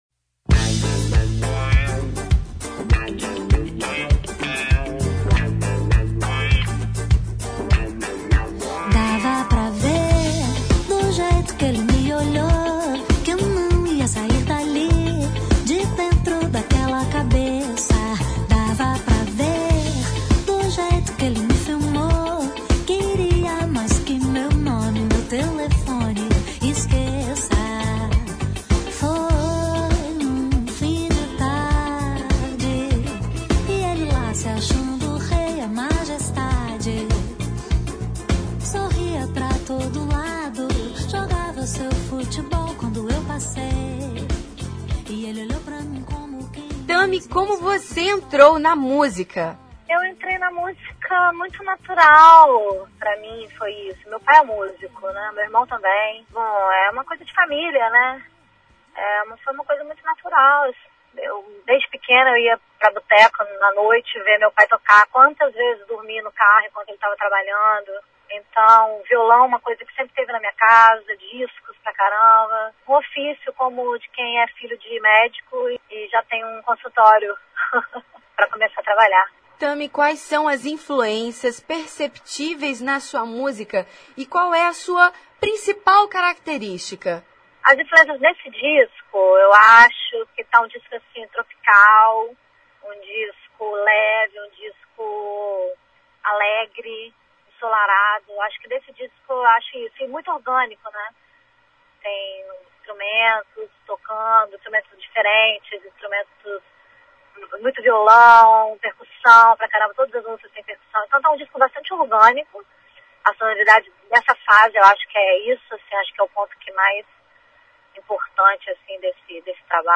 Confira a entrevista completa com a cantora.